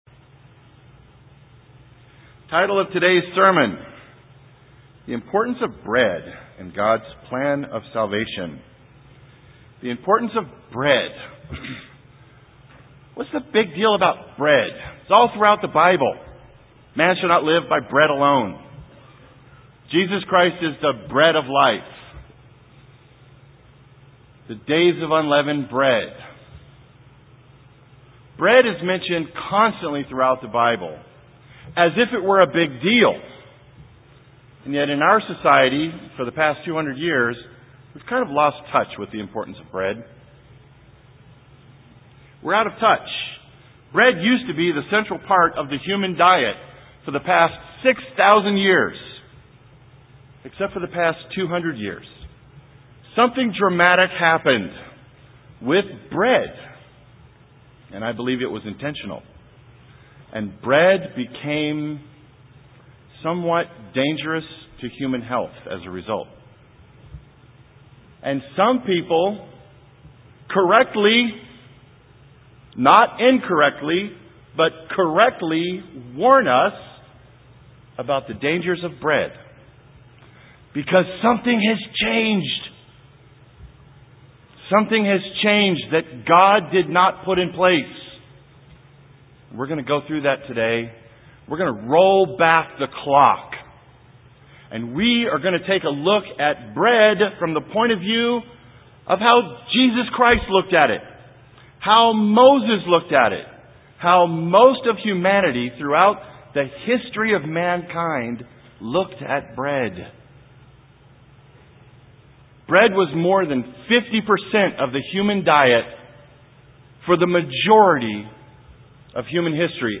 This sermon discusses three aspects breads relationship to the symbolism of the Days of Unleavened Bread.